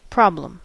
Ääntäminen
US : IPA : [ˈpɹɑb.ləm]